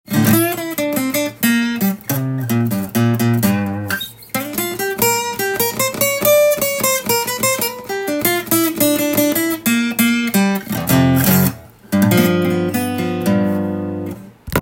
ピックで弾いてみましたが、
やはり昔の音がします。
合いそうなギターサウンドです。
ジャズやボサノバなどを演奏するとそれらしい雰囲気が出せそうですね！